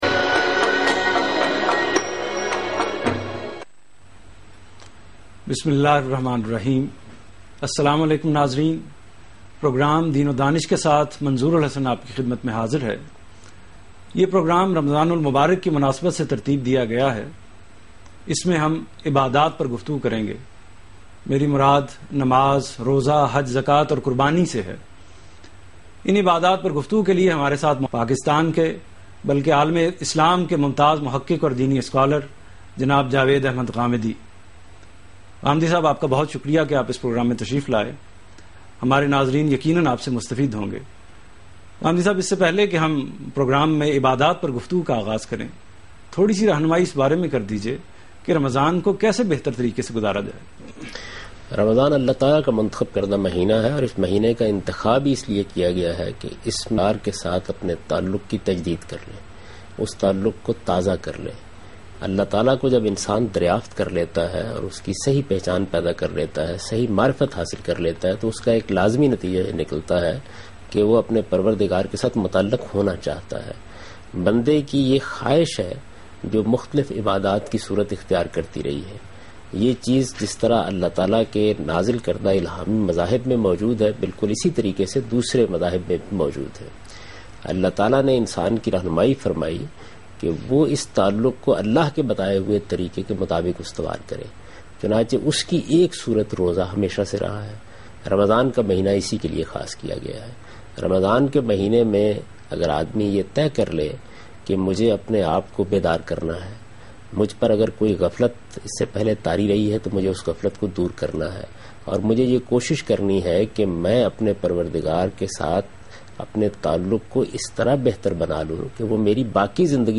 Javed Ahmed Ghamidi in Aaj TV Program Deen o Danish Ramzan Special.